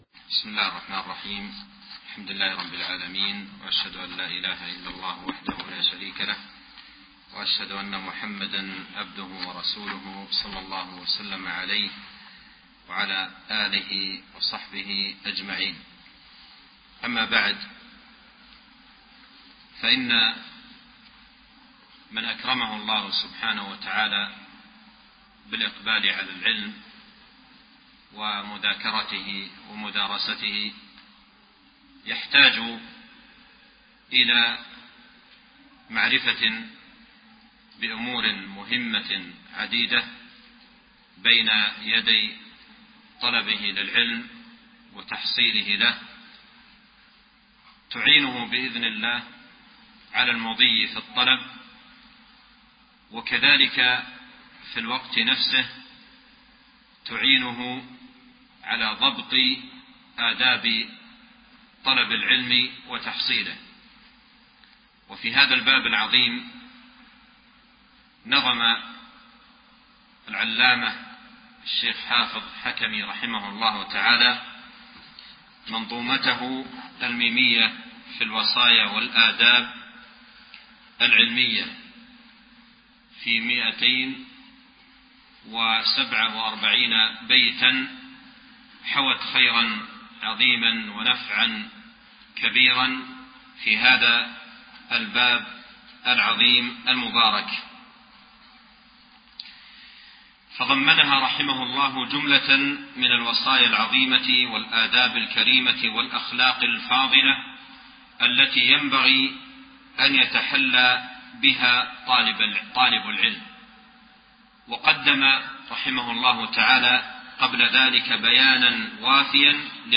المحاضرات - Page 2 of 631 - موقع دروس الإمارات